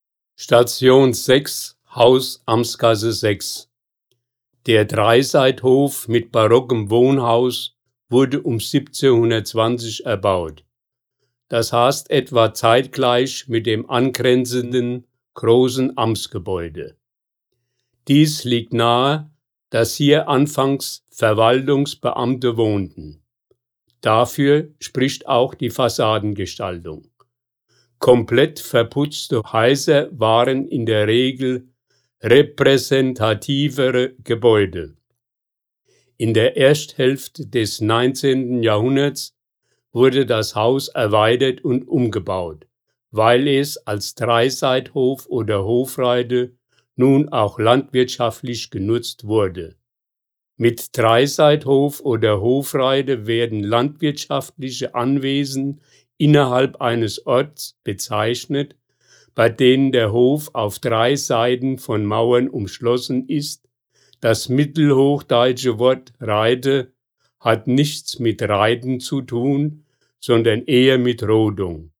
Hier können Sie die Hörfassung der Stationsbeschreibung abspielen!